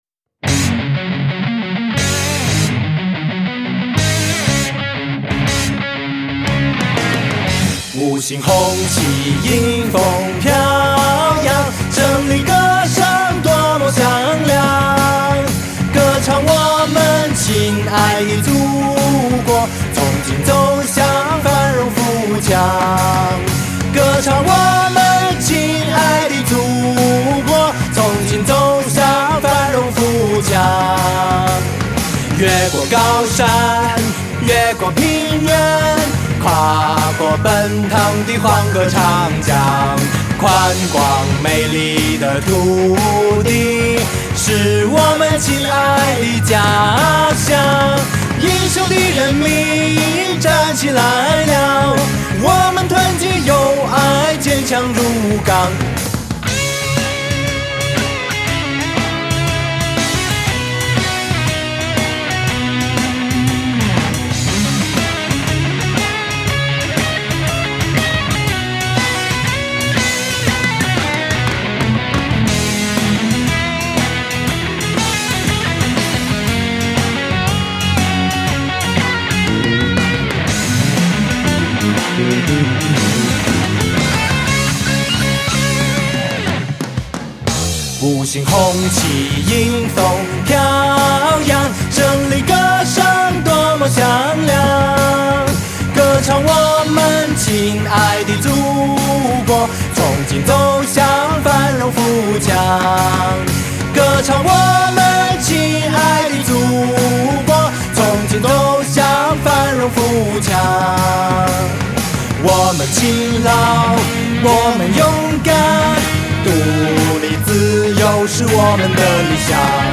精选60首经典革命红歌 勾起属于你的记忆